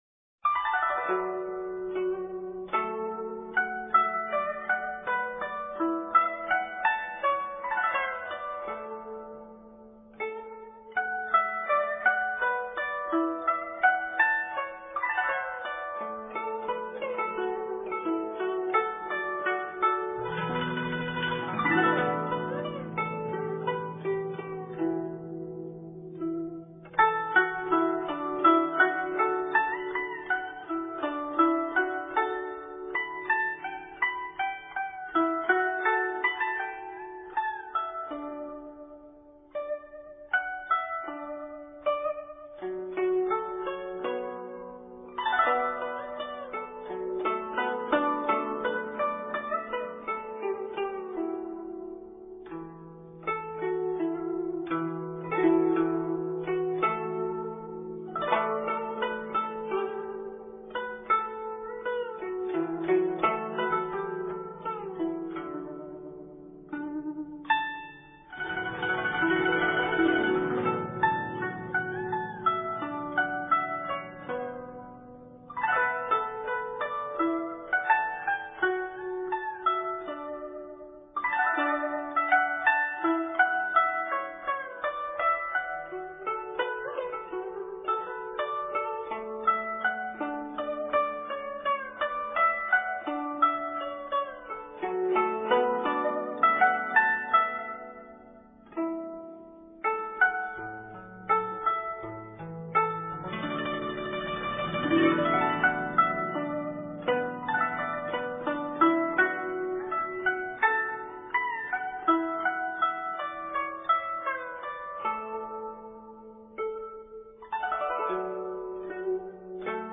演奏：揚琴
曲中表現了作者對西湖秀麗景色的感受，曲調既採用了浙江的民間音樂，又有廣東音樂的風格，它是中國器樂作品中最出色的旋律之一。